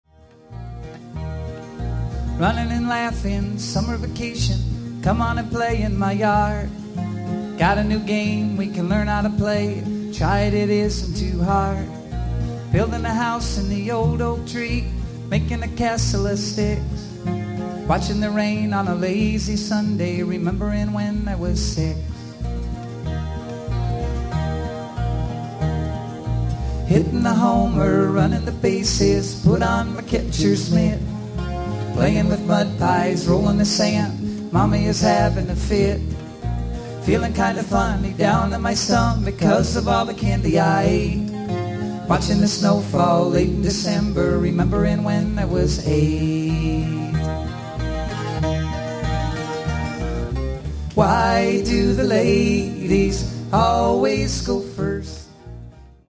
guitar and banjo
including some live recordings